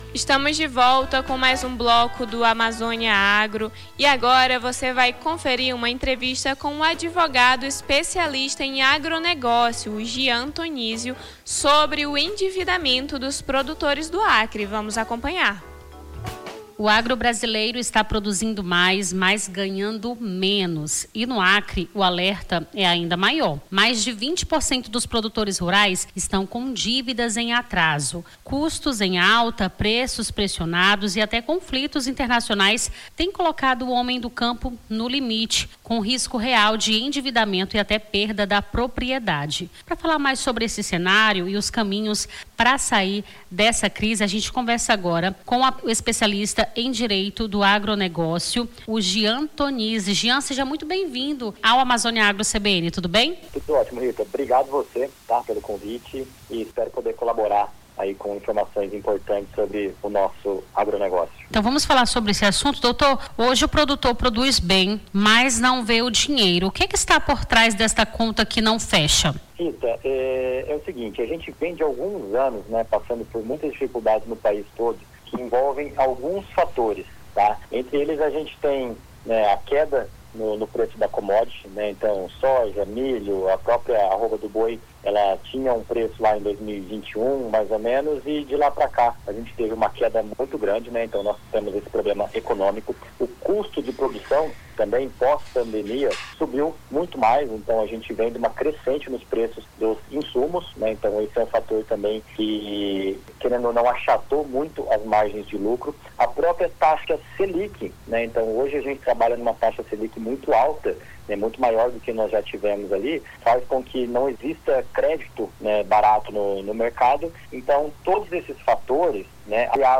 Na manhã deste sábado, 20, conversamos com o advogado especialista em agronegócio